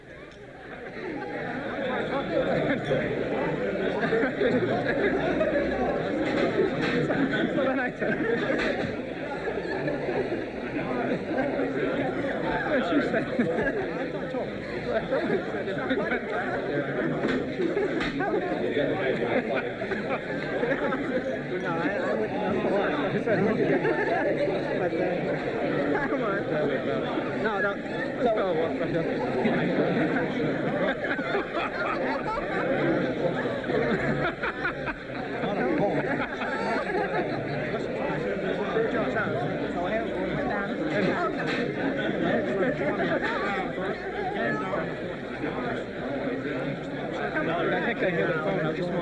11. Болтают